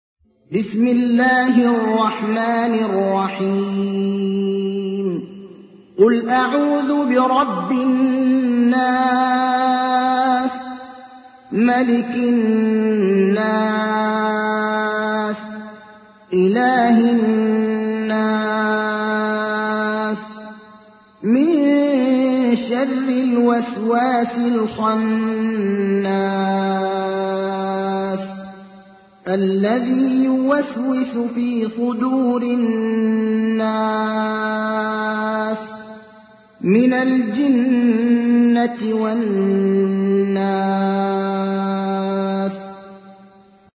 تحميل : 114. سورة الناس / القارئ ابراهيم الأخضر / القرآن الكريم / موقع يا حسين